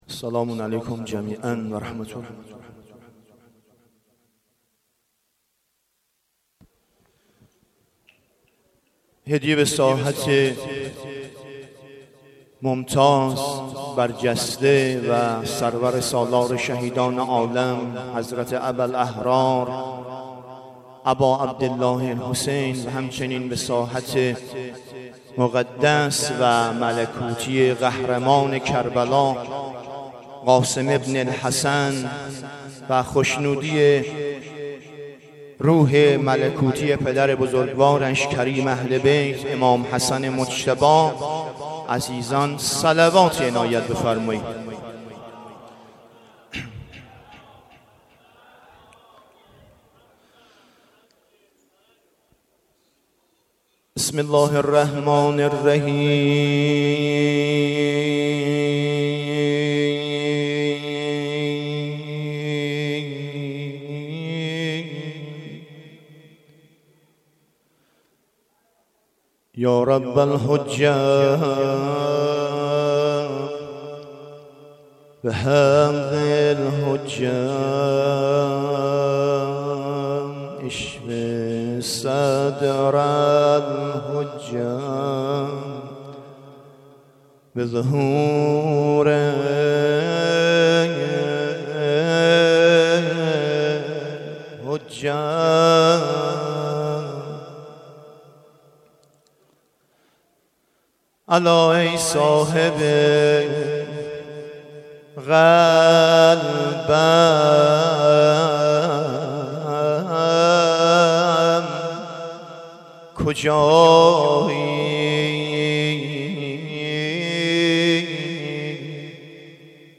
نوحه
مسجد جامع گیلاکجان مراسم عزاداری شب ششم محرم سال 1396 دهه اول محرم